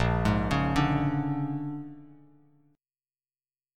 Bb7#9 chord